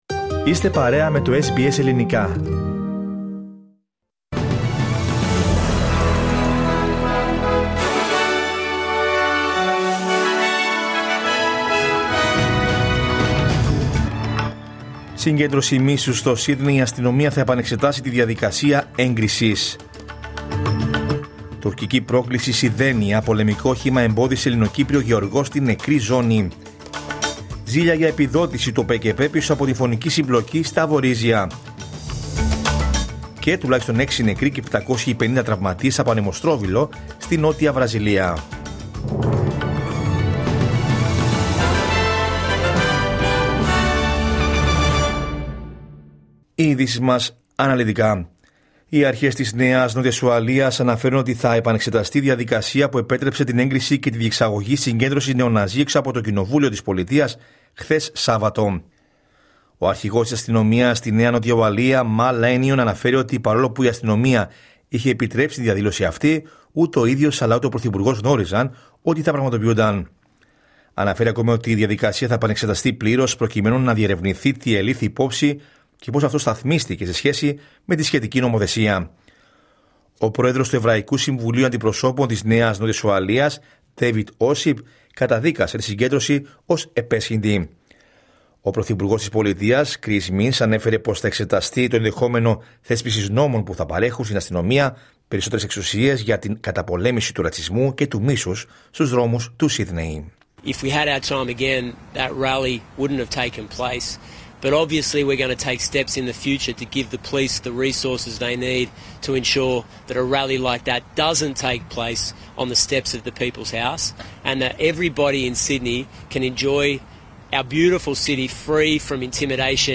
Ειδήσεις: Κυριακή 9 Νοεμβρίου 2025